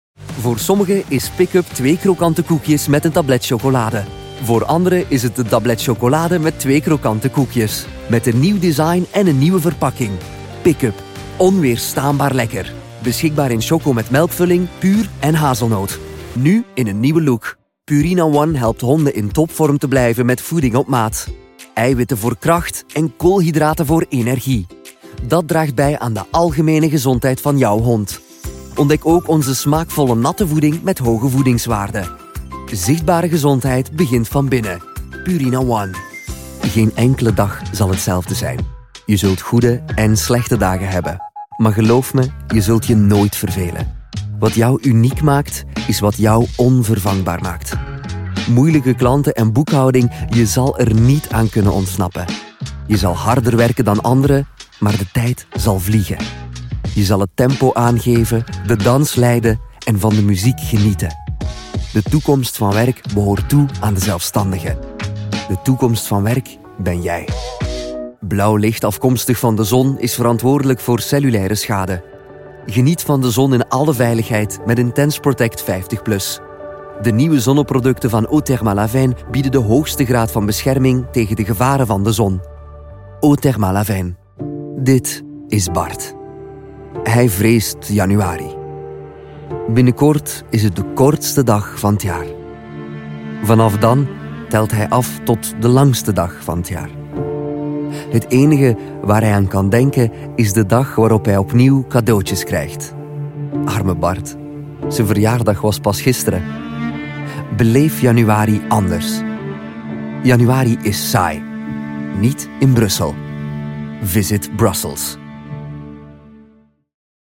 Sprechprobe: Werbung (Muttersprache):
Commercials